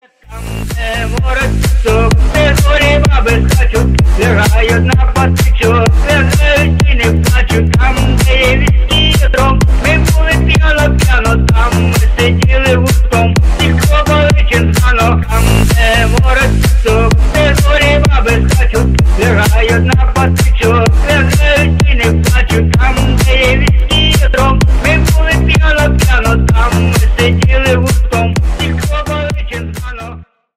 • Качество: 320, Stereo
прикольные
Прикольный ремикс на песню из тиктока